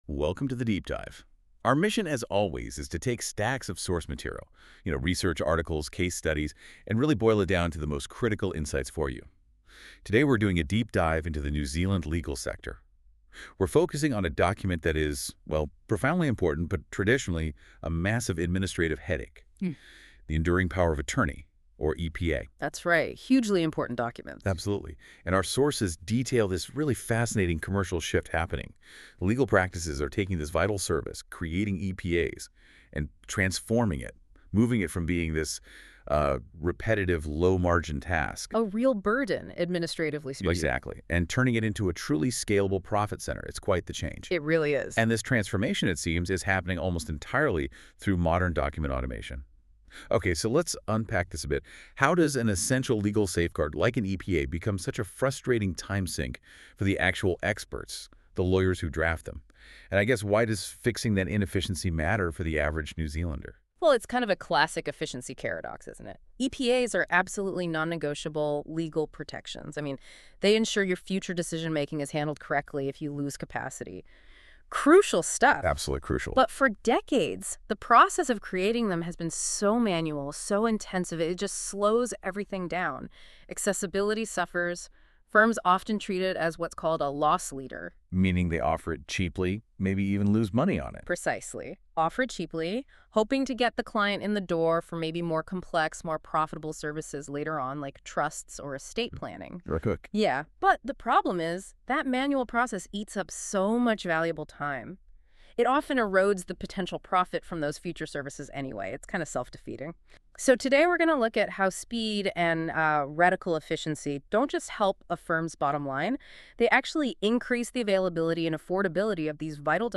Google Notebook LM - Automating Enduring Powers of Attorney in New Zealand Law Firms.m4a